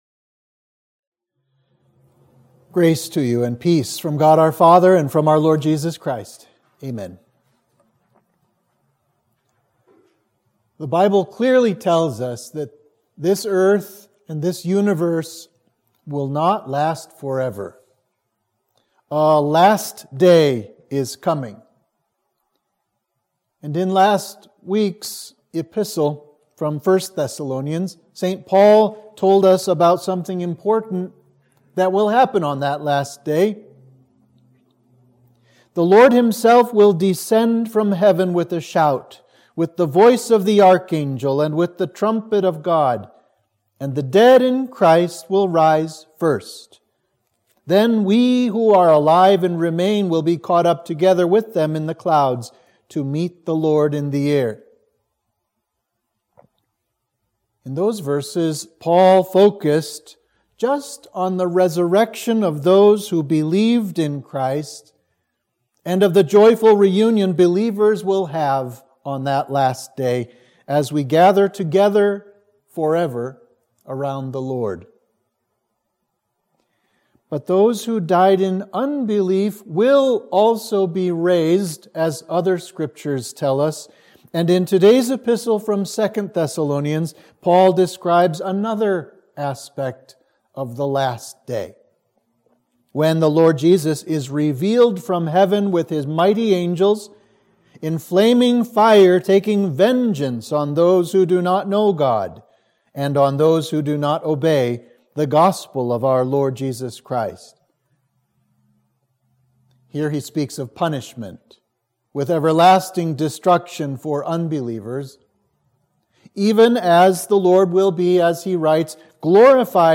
Sermon for Trinity 26